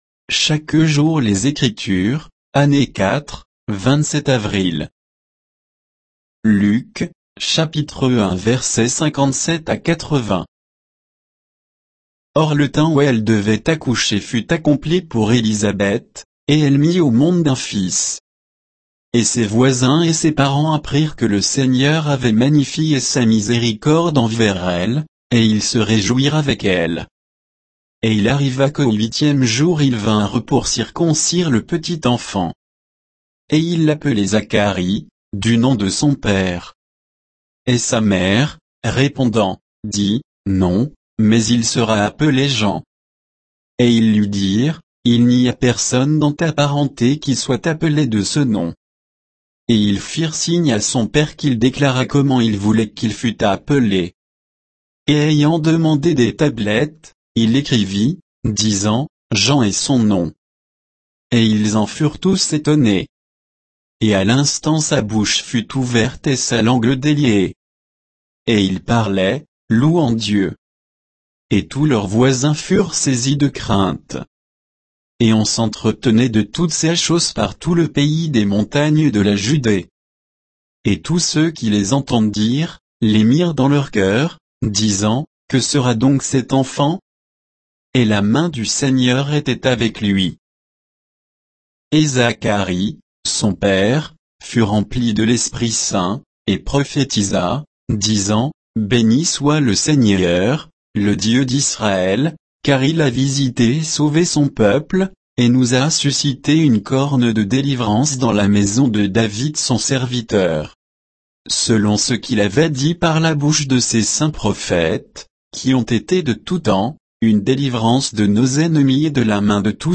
Méditation quoditienne de Chaque jour les Écritures sur Luc 1, 57 à 80